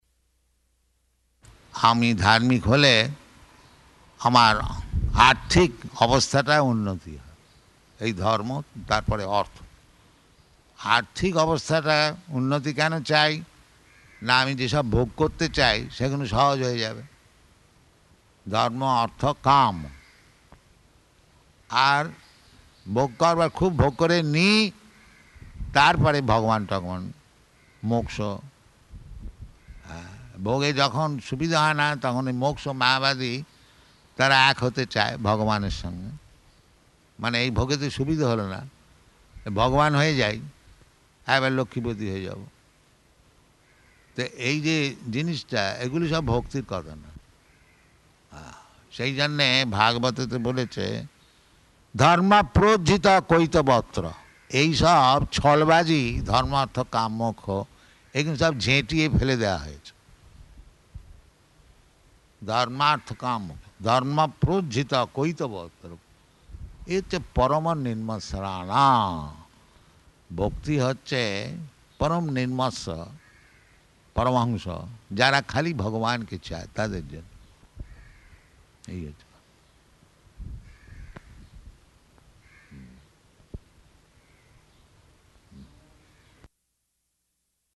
Lecture in Bengali
Location: Māyāpur